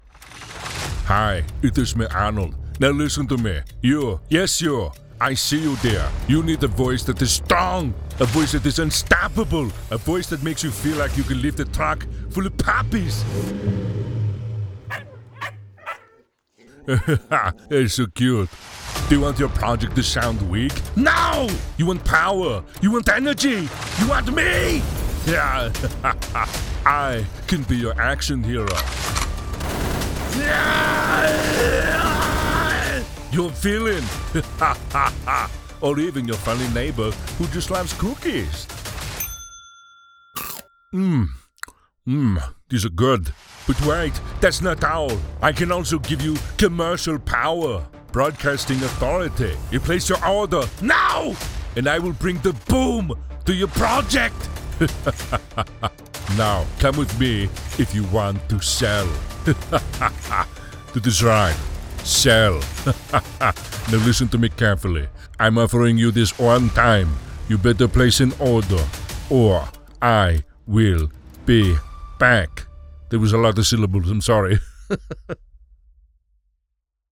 Impersonations
Arnold Schwarzenegger Demo
0120Arnold_Schwarzenegger_Demo.mp3